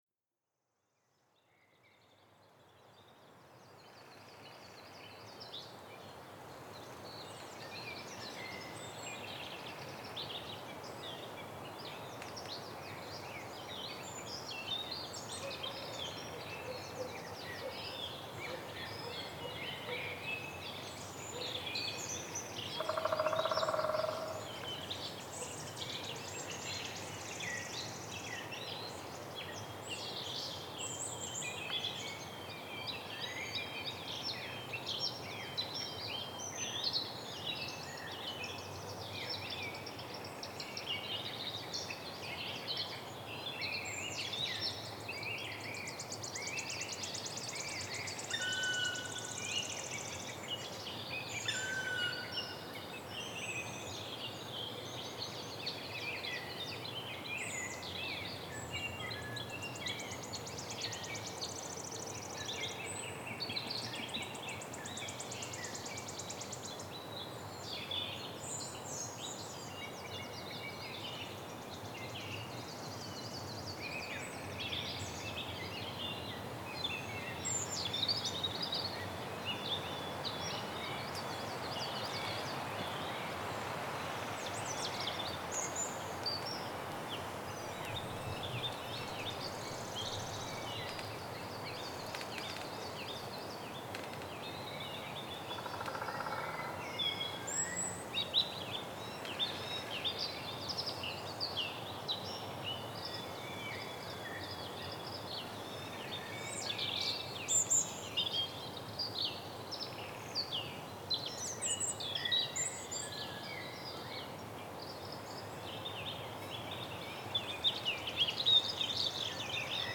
Sounds of the spring wood in Moscow area.
Category 🌿 Nature
ambiance ambience ambient area atmos atmosphere background-sound bark sound effect free sound royalty free Nature